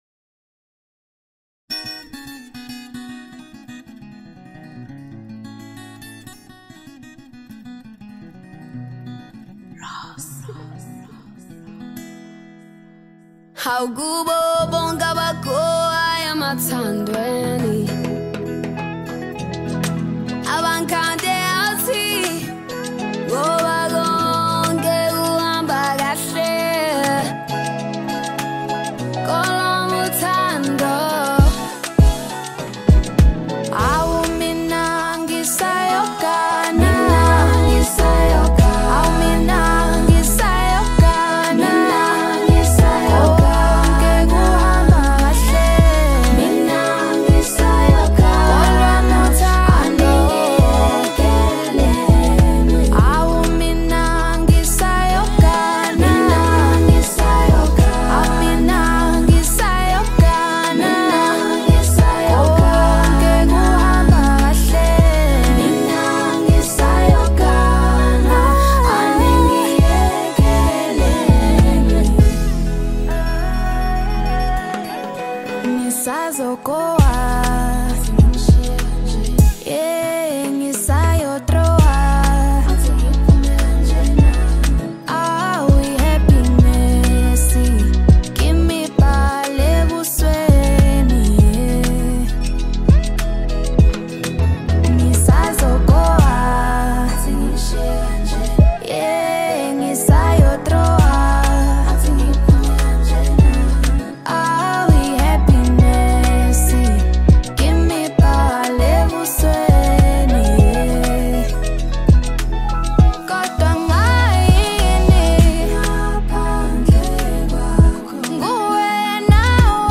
Amapiano, DJ Mix, Lekompo